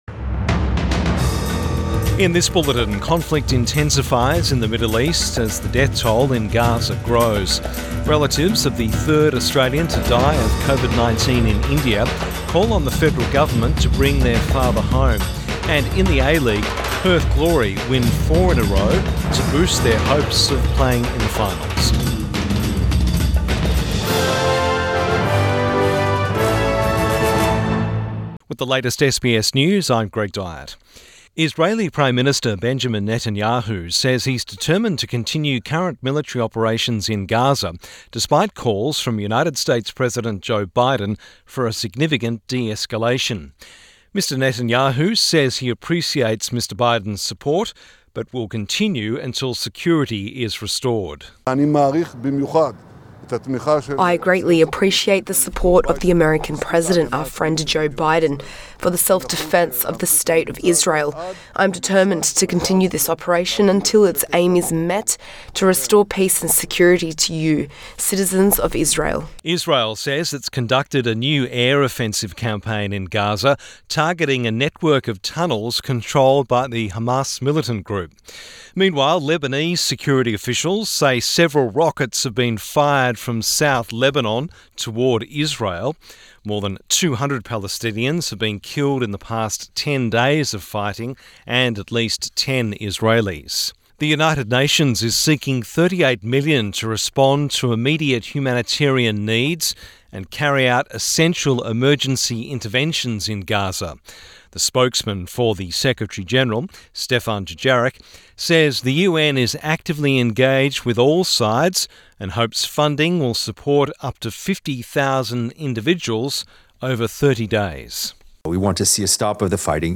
AM bulletin 20 May 2021